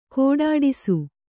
♪ kōḍāḍisu